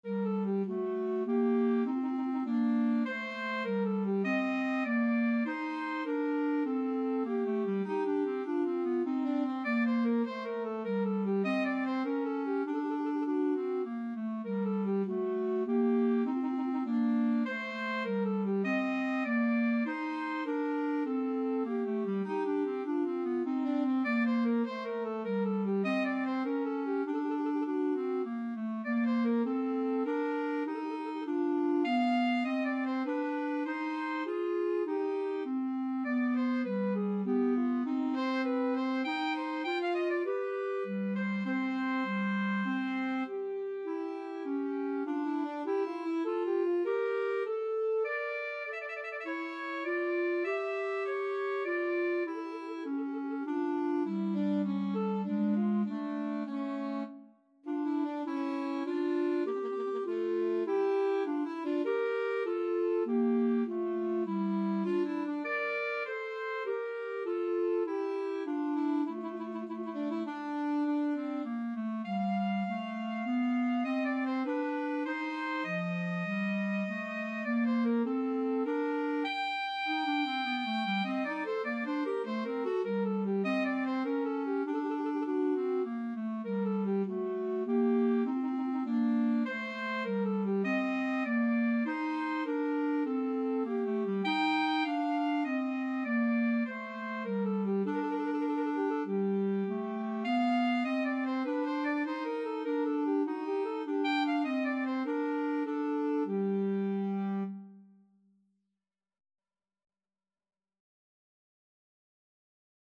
Alto SaxophoneClarinet
3/4 (View more 3/4 Music)
Tempo di menuetto